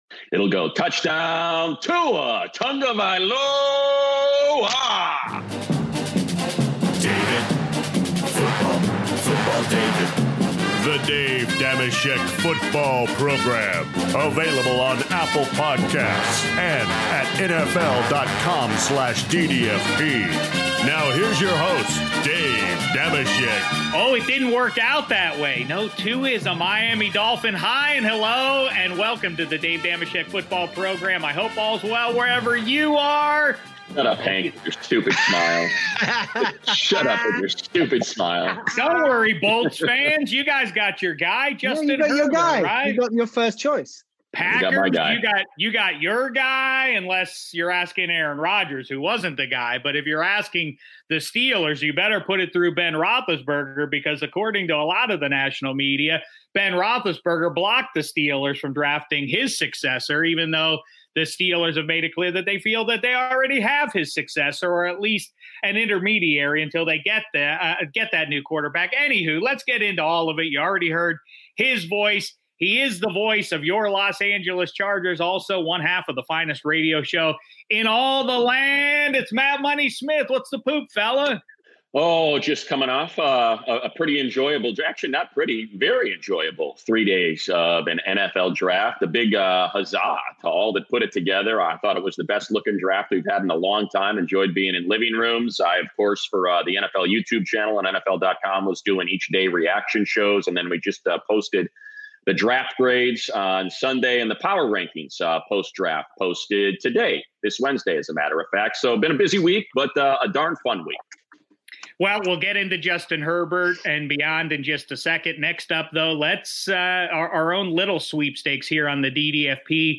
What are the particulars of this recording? via video chat for a mid-week DDFP!